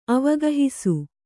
♪ avagahisu